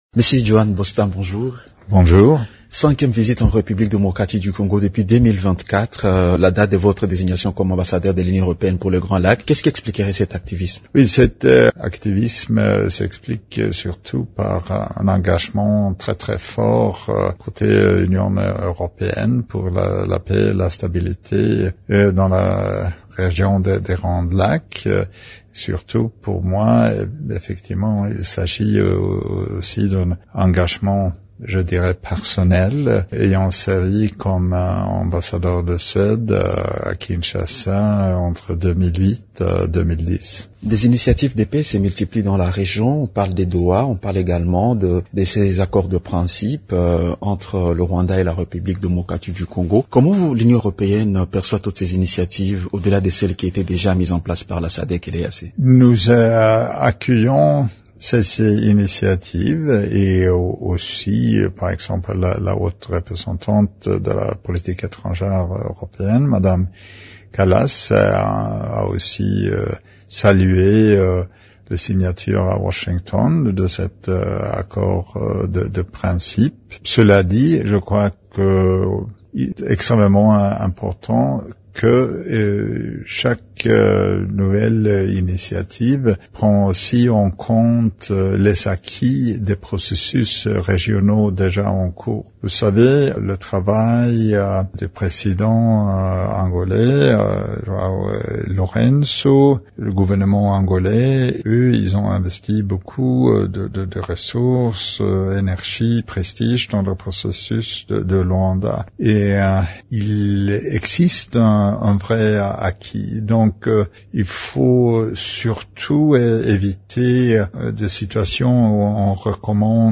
Il l’a dit ce jeudi 1er mai dans une interview accordée à Radio Okapi.